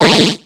Cri de Barpau dans Pokémon X et Y.